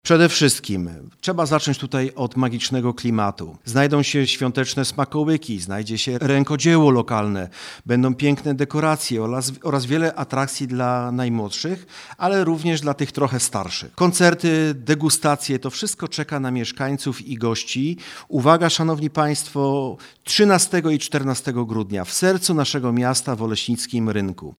– Jesteśmy dumni z naszego Jarmarku Bożonarodzeniowego – mówi Adam Horbacz, burmistrz Oleśnicy.